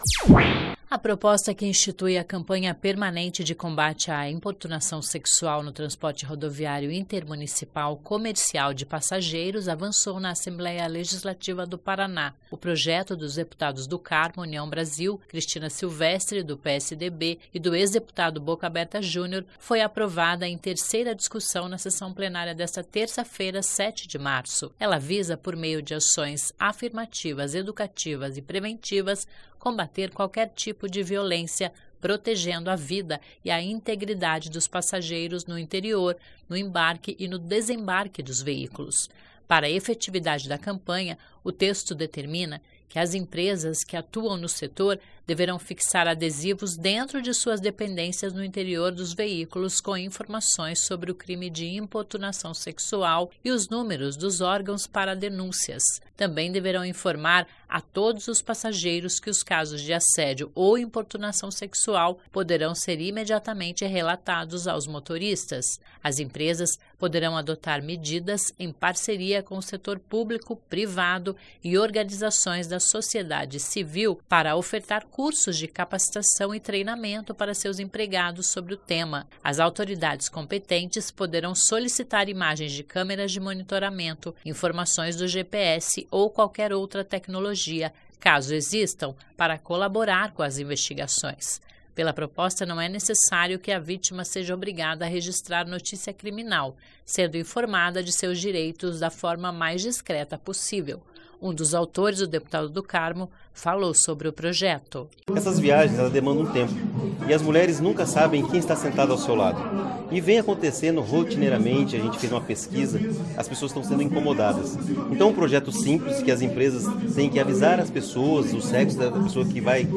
Um dos autores, o deputado Do carmo falou sobre o projeto.
(Sonora)